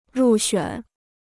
入选 (rù xuǎn): être sélectionné; être choisi.